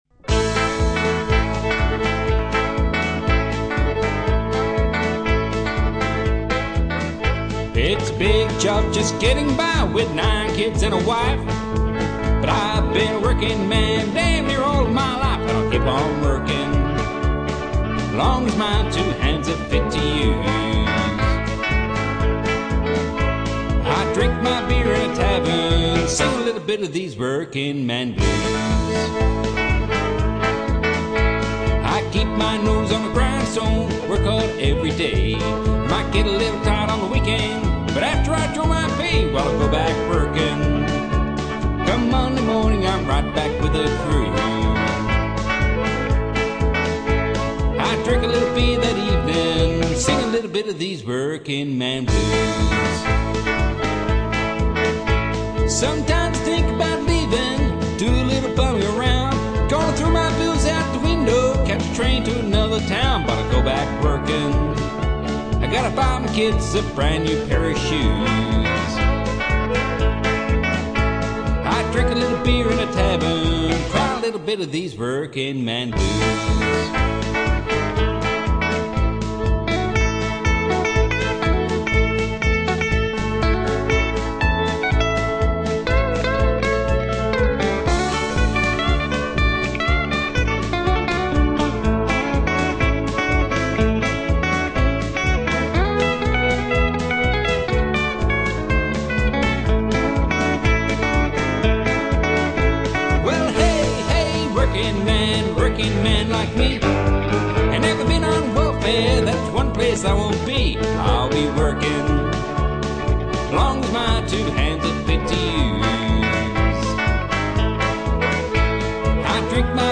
Steel Guitar
Fiddle / Keyboard / Backing Vocals
Drums / Backing Vocals